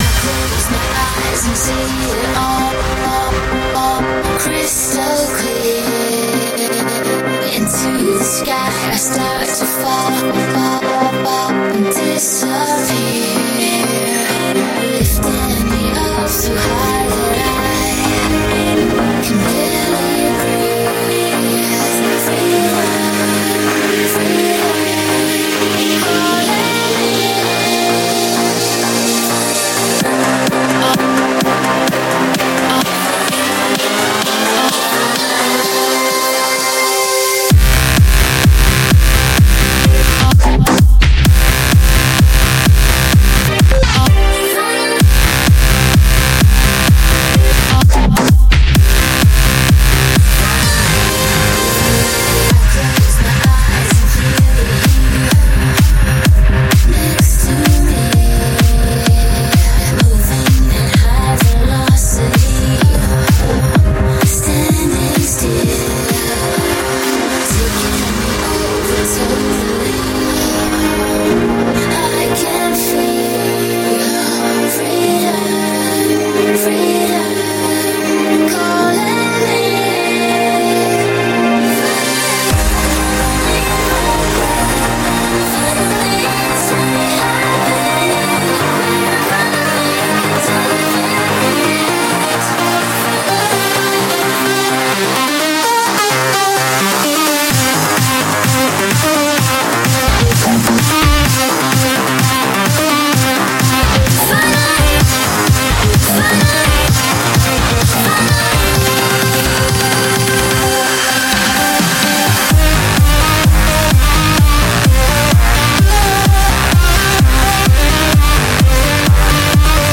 BPM128
Audio QualityMusic Cut
CommentairesAn underlooked electro trance song from 2013.